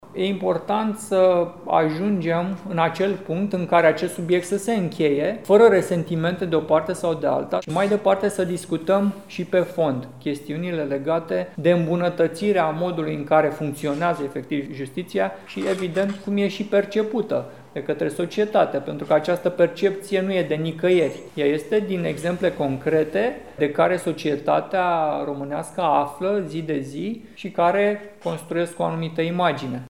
Legea care reglementează pensiile magistraților și vârsta la care aceștia pot ieși din activitate nu este îndreptată împotriva judecătorilor și procurorilor, a declarat astăzi, într-o conferință de presă, Stelian Ion, deputat USR de Constanța.